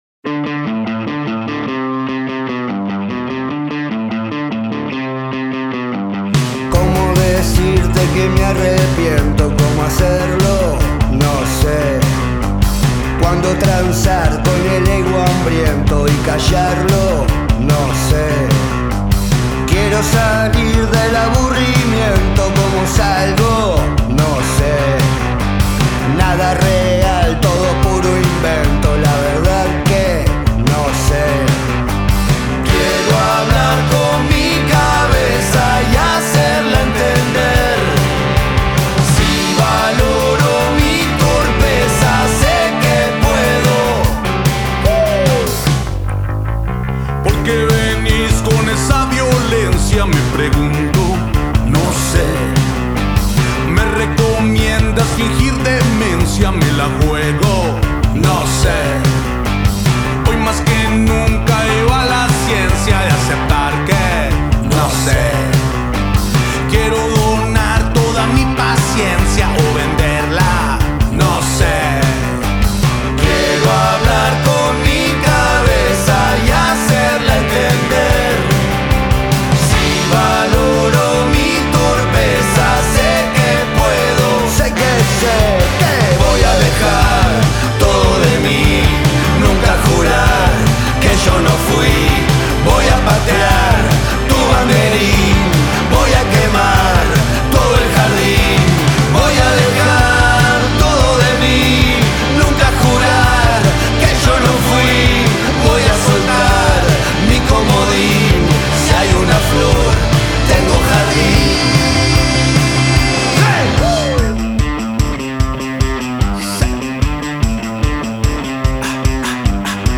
banda uruguaya de rock